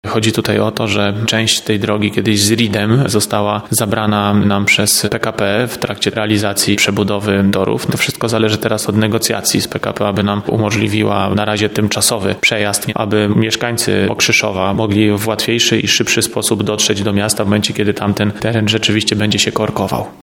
– Tak naprawdę dzika droga istnieje i biegnie wzdłuż torów, od ronda przy ulicy Skłodowskiej Curie do przejazdu kolejowego na ulicy Zakrzowskiej – tłumaczy prezydent Tarnobrzega Łukasz Nowak.